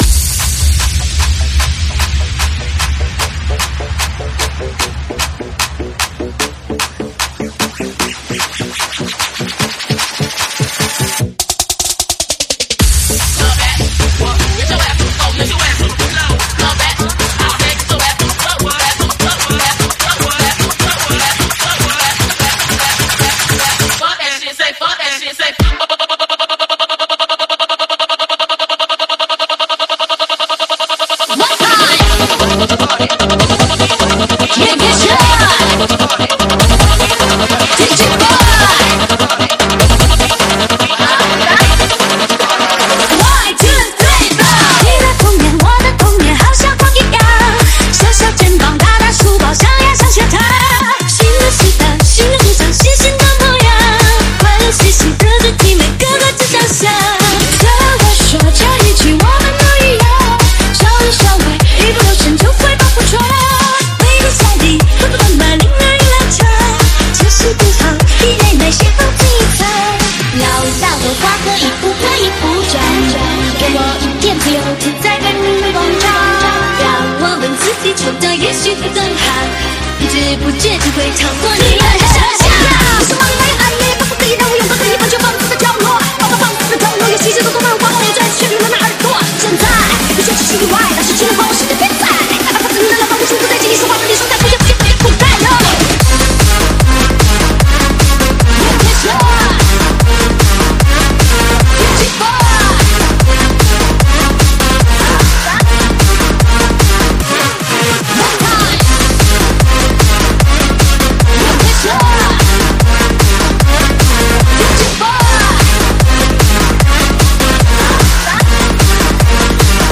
试听文件为低音质，下载后为无水印高音质文件 M币 8 超级会员 免费 购买下载 您当前未登录！